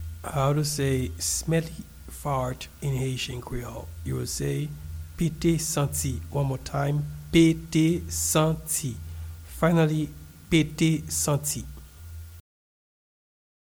Pronunciation and Transcript:
smelly-fart-in-Haitian-Creole-Pete-santi-pronunciation.mp3